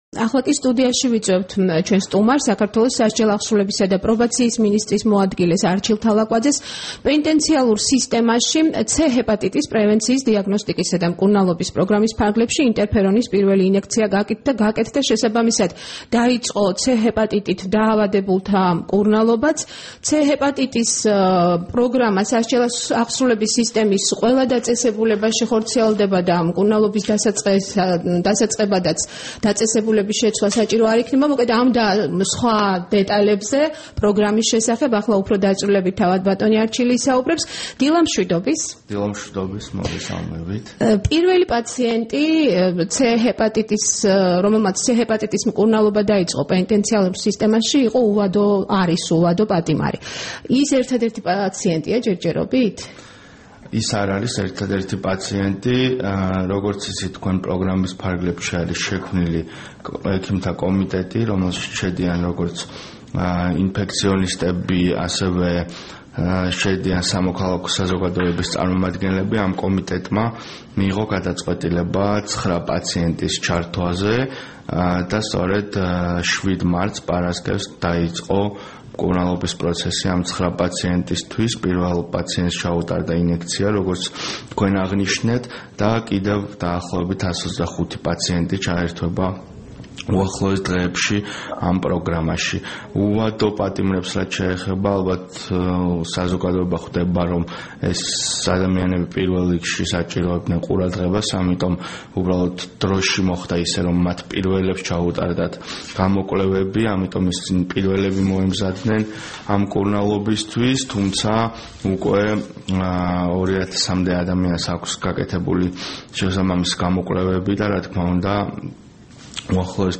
10 მარტს რადიო თავისუფლების დილის გადაცემის სტუმარი იყო სასჯელაღსრულებისა და პრობაციის მინისტრის მოადგილე არჩილ თალაკვაძე.
საუბარი არჩილ თალაკვაძესთან